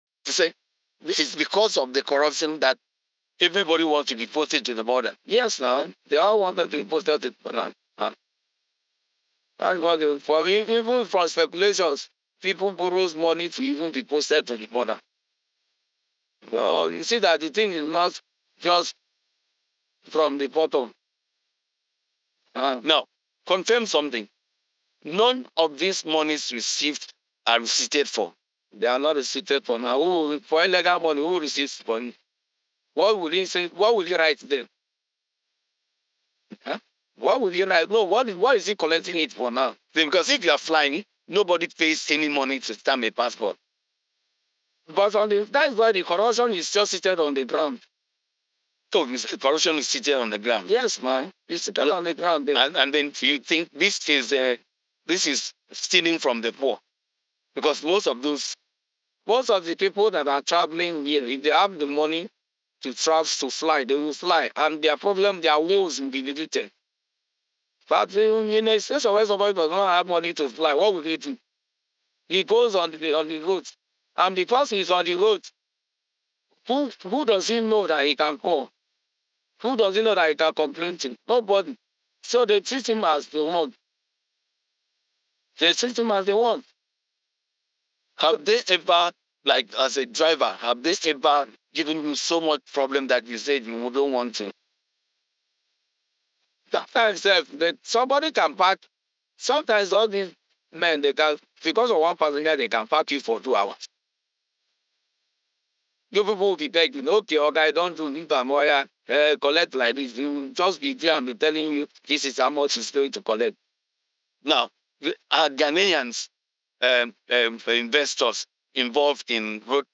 Anonymous driver sharing his experience with our reporter (Credit: GuardPost Nigeria)